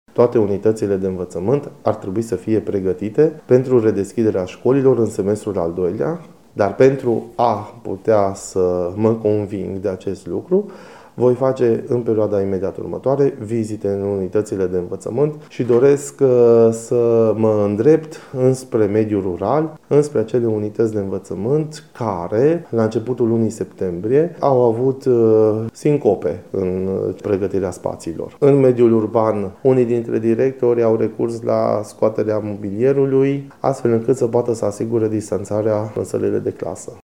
În școlile mari din mediul urban principala preocupare va fi asigurarea distanței fizice pentru care s-a recurs inclusiv la eliminarea dulapurilor și a podiumului din fața tablei, spune inspectorul școlar :